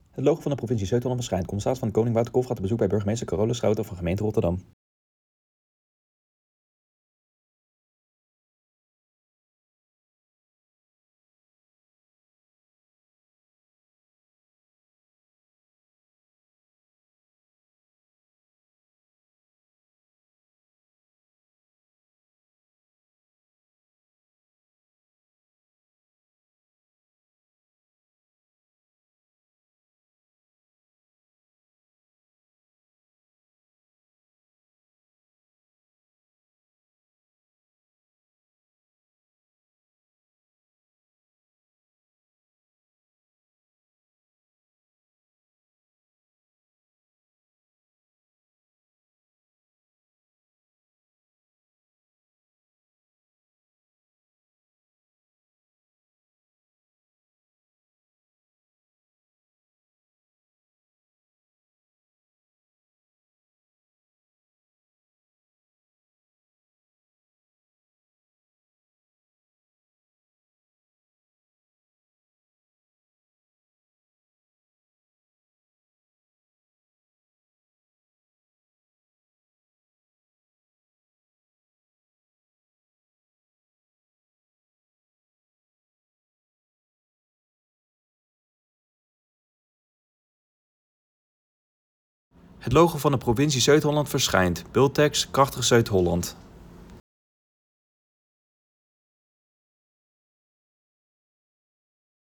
CdK in gesprek met burgemeester Rotterdam
De commissaris van de Koning bezoekt de komende tijd alle 50 gemeenten van Zuid-Holland. In deze video gaat hij in gesprek met de burgemeester van Rotterdam.